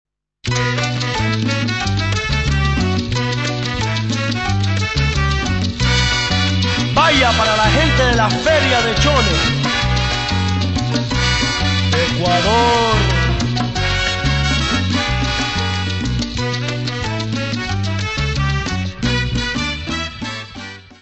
: stereo; 12 cm
salsa